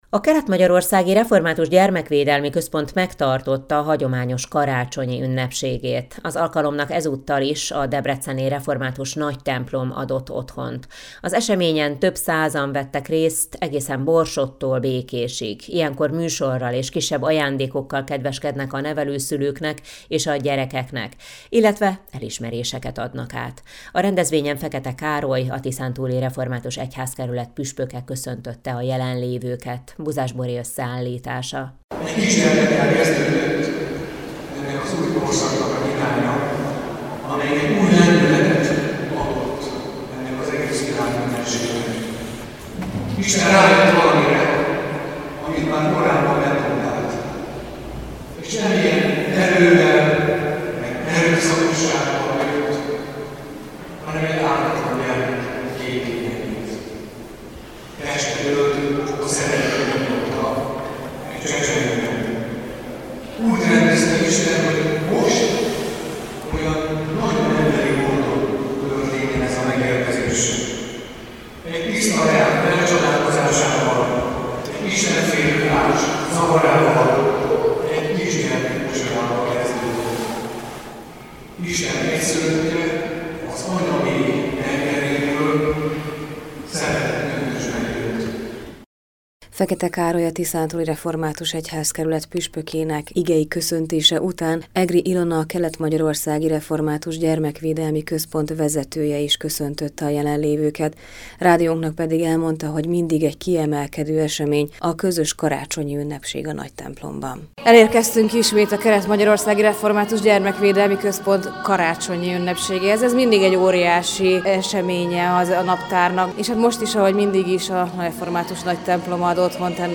Az alkalomnak ezúttal is a debreceni Református Nagytemplom adott otthont.
Ilyenkor műsorral és kisebb ajándékokkal kedveskednek a nevelőszülőknek és a gyermekeknek, illetve elismeréseket adnak át. A rendezvényen Fekete Károly, a Tiszántúli Református Egyházkerület püspöke köszöntötte a jelenlévőket.